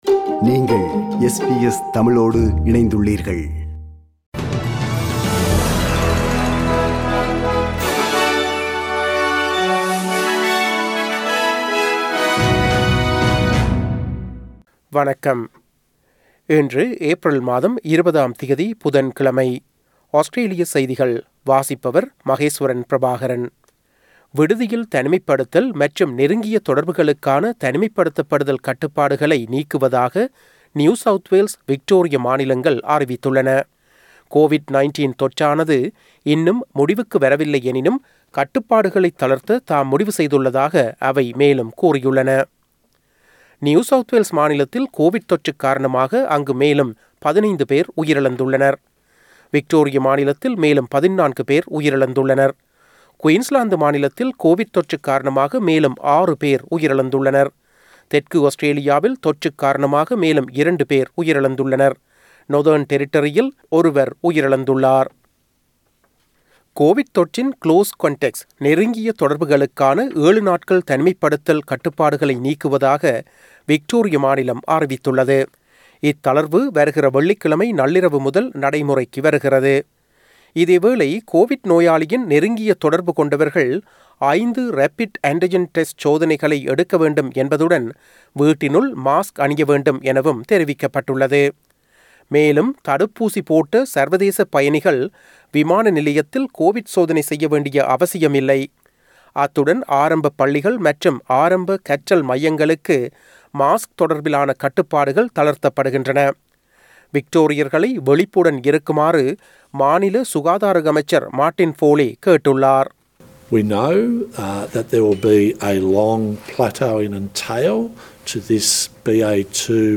Australian news bulletin for Wednesday 20 April 2022.